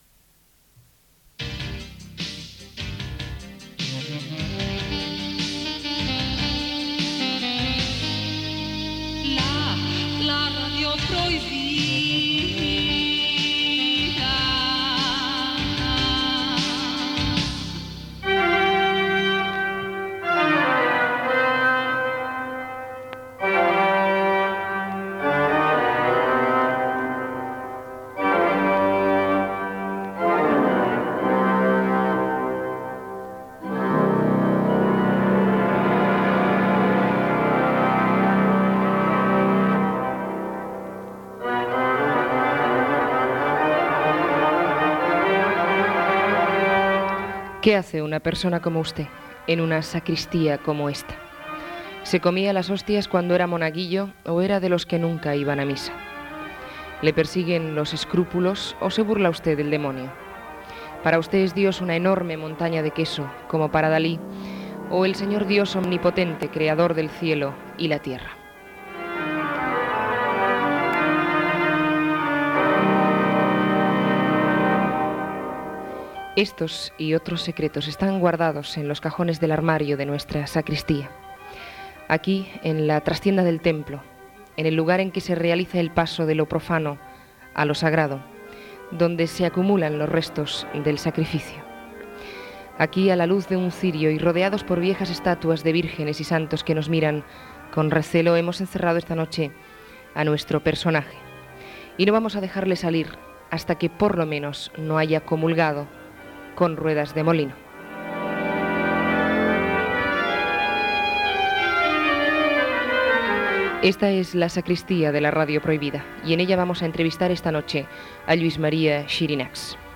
Secció "La sacristía", amb una entrevista a l'activista polític i social, senador i sacerdot Lluís Maria Xirinacs Gènere radiofònic Entreteniment